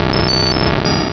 Cri de Magnéton dans Pokémon Rubis et Saphir.